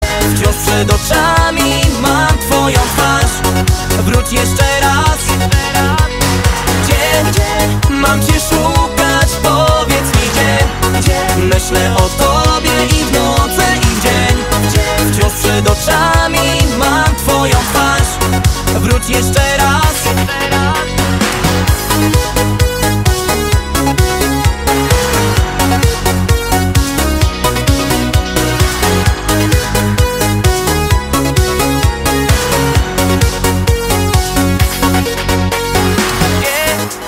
Kategorie Disco Polo